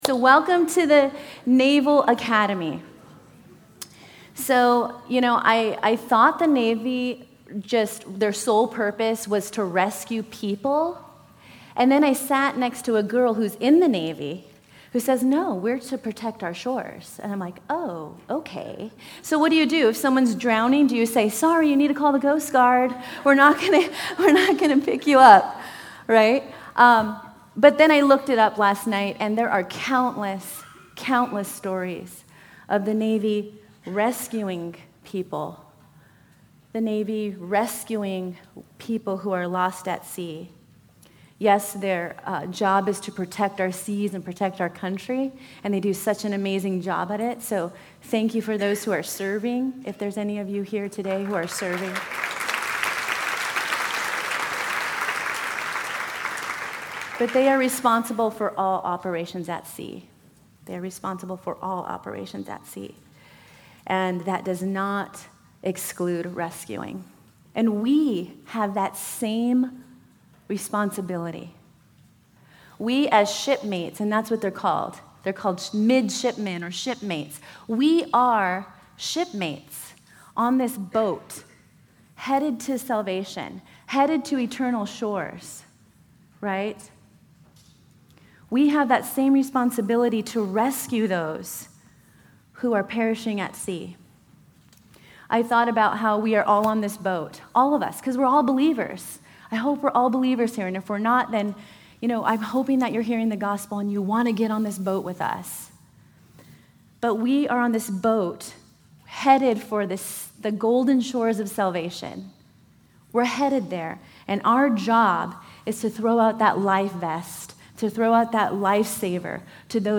Women's Retreat 2014